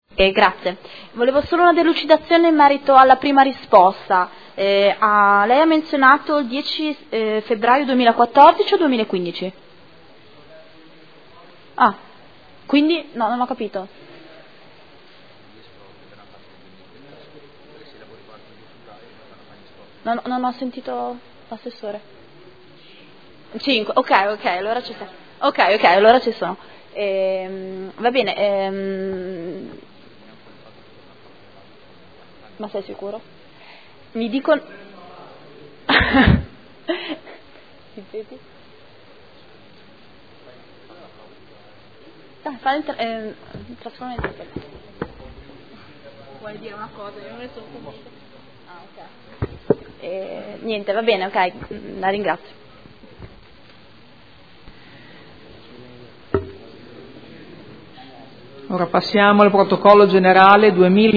Elisabetta Scardozzi — Sito Audio Consiglio Comunale
Interrogazione del gruppo consiliare Movimento 5 Stelle avente per oggetto: “Situazione inerente progettazione, espropri, incarichi e avanzamento lavori della realizzazione della cassa di espansione del canale Naviglio, in località prati di San Clemente nei territori di Modena, Bomporto e Bastiglia” – Primo firmatario consigliera Scardozzi. Replica